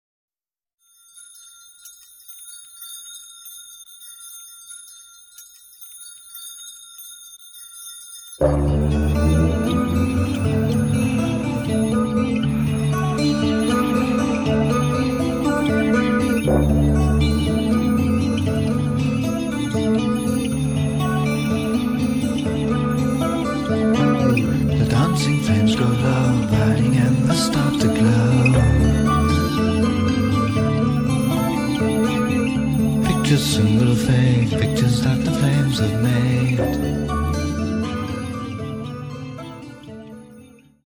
ギター
ドラム
ベース
キーボード